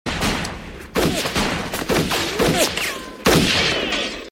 GUNSHOTS NEARBY.mp3
Original creative-commons licensed sounds for DJ's and music producers, recorded with high quality studio microphones.
gunshots_nearby_w2c.ogg